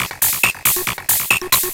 DS 138-BPM A6.wav